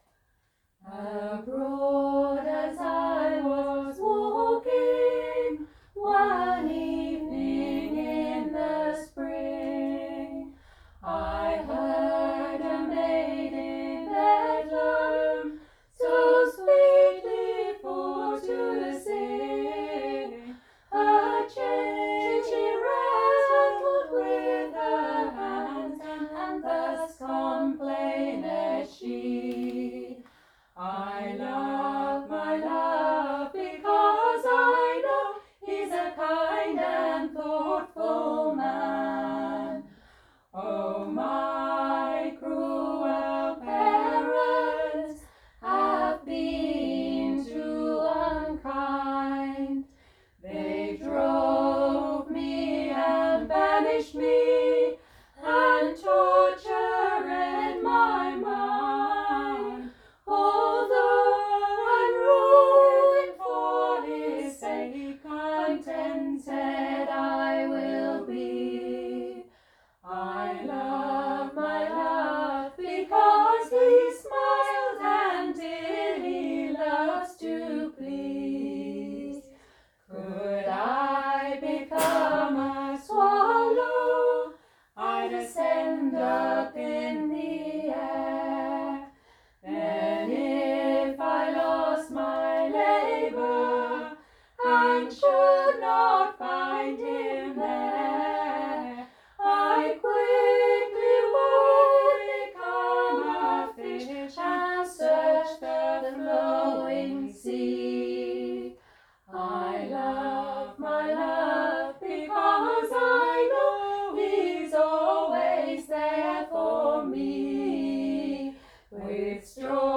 The three vocal artists who came together for this collaboration fed back that they thoroughly enjoyed the opportunity offered to spend time reworking this classic Cornish tune.